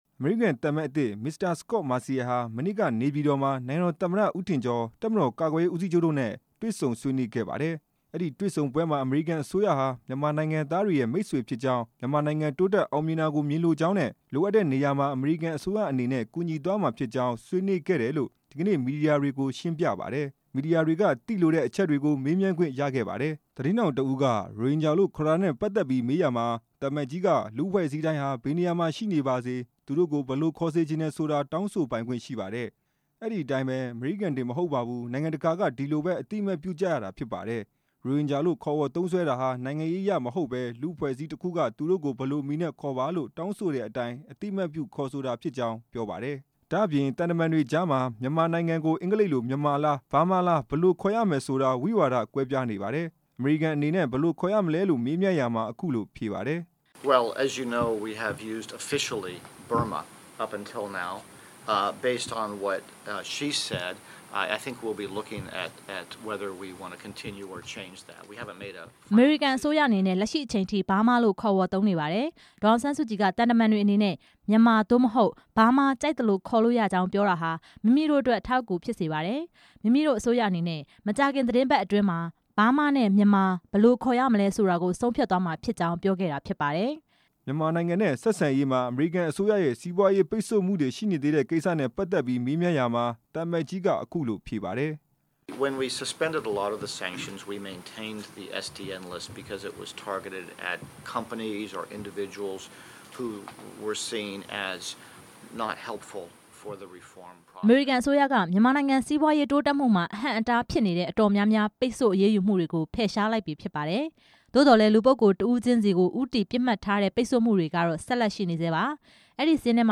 အမေရိကန်သံအမတ်အသစ် သတင်းစာရှင်းလင်းပွဲ